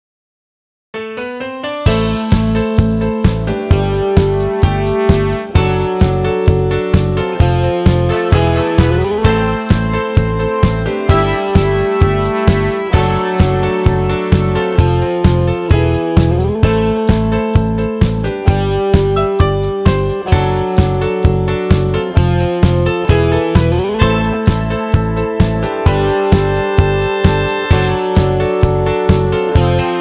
Patter